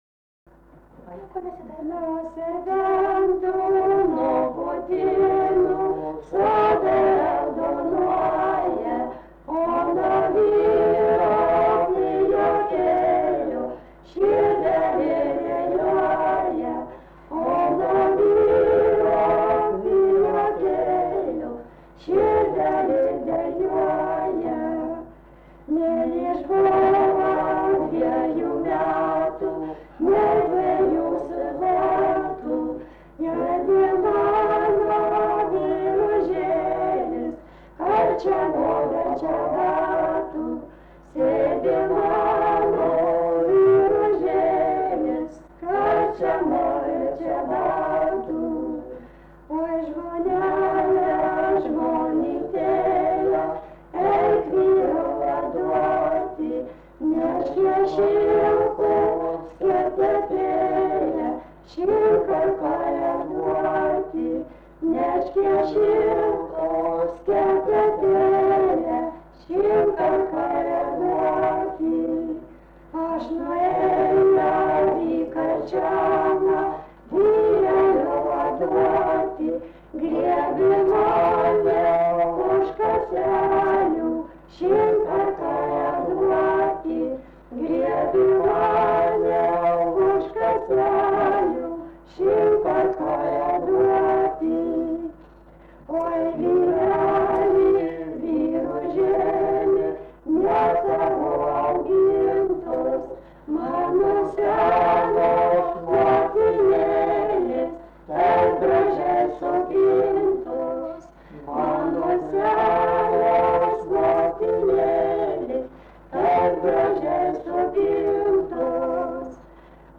daina, šeimos